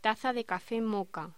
Locución: Taza de café moka